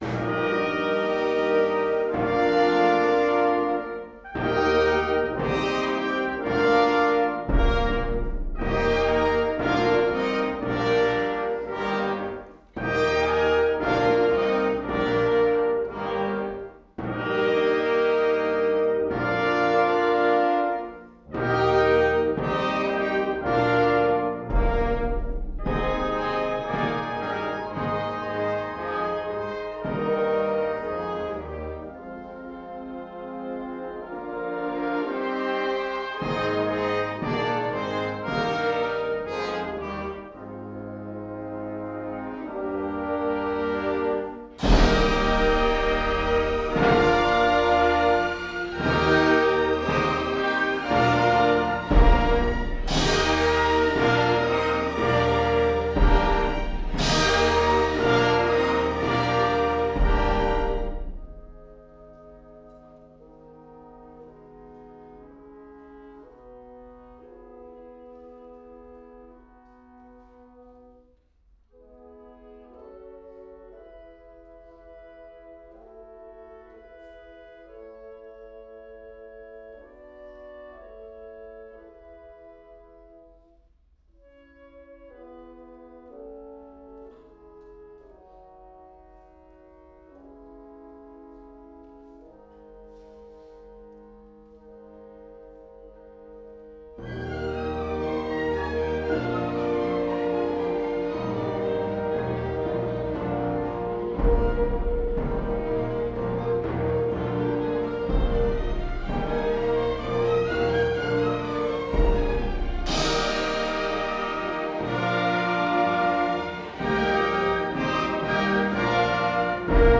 Home > Music > Jazz > Bright > Floating > Build Up Scenes